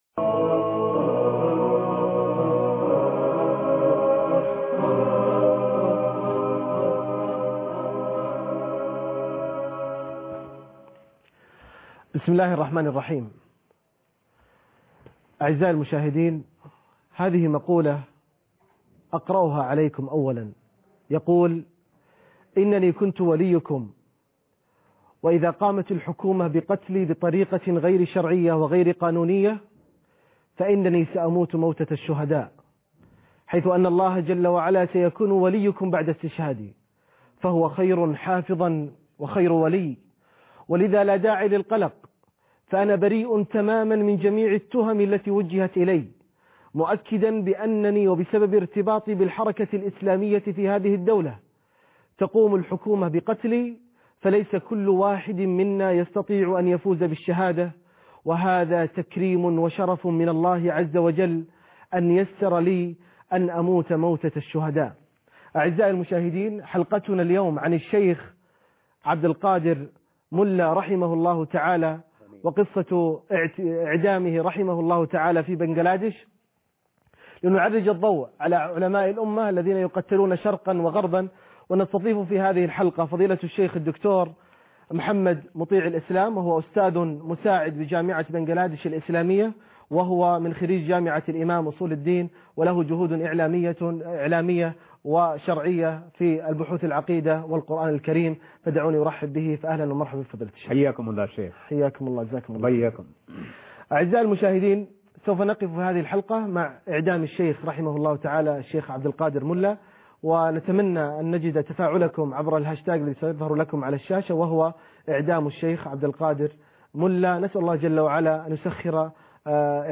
لقاء عن اعدام الشيخ/عبد القادر ملا فى بنجلاديش ( 15/12/2013 ) لقاء خاص - قسم المنوعات